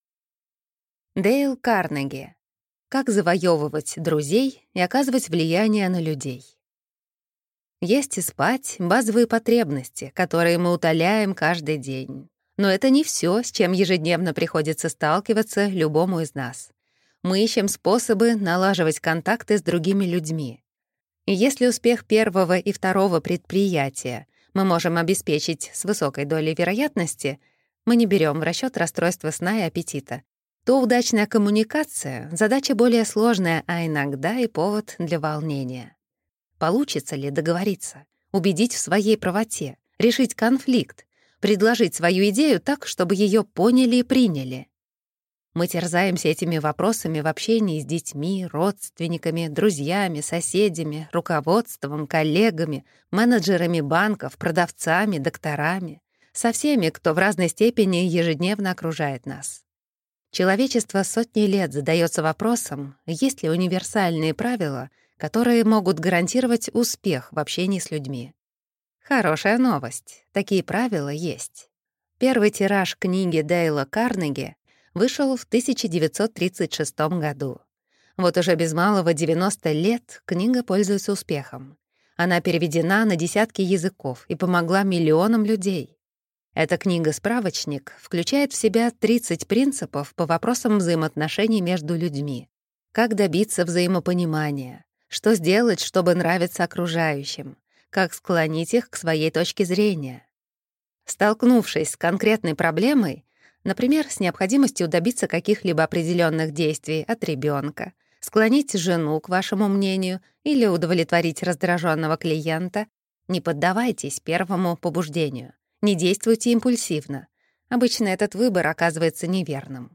Аудиокнига 5 главных книг по общению в экспертном изложении. Книга 2. Как завоевывать друзей и оказывать влияние на людей – Дейл Карнеги | Библиотека аудиокниг